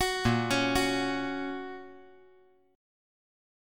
Bbm#5 Chord
Listen to Bbm#5 strummed